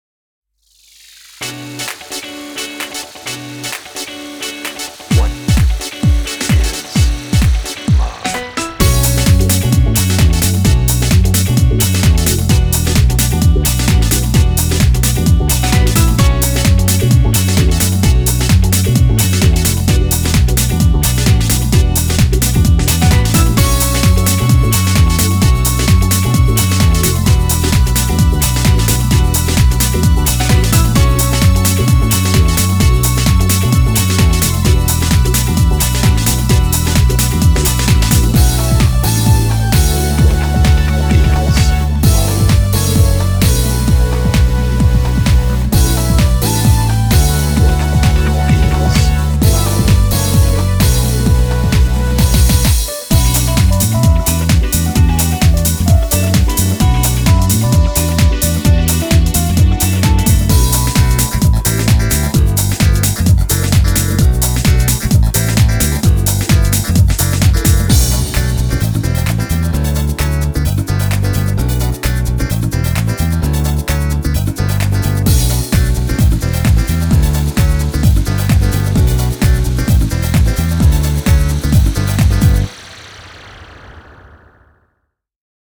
BPM130-131
Audio QualityPerfect (High Quality)